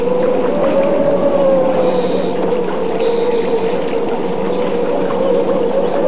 wind15.mp3